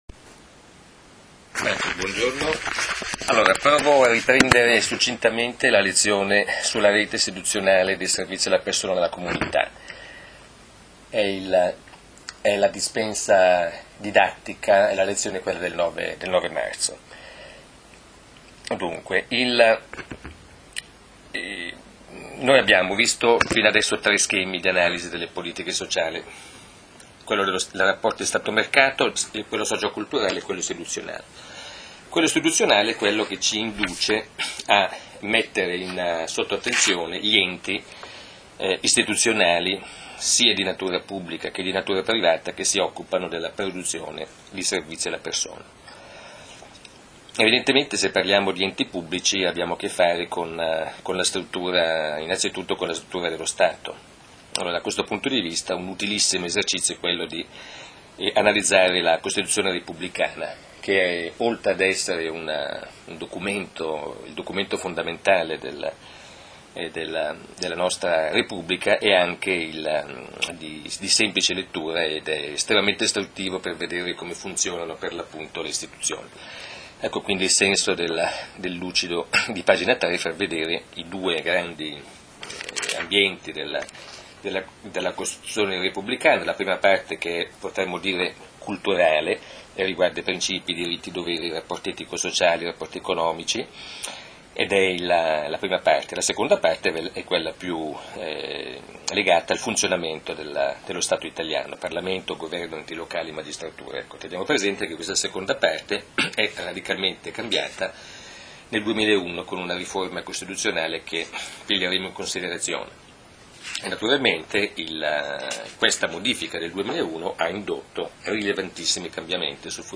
lezione tenuta alla Università di Milano – Bicocca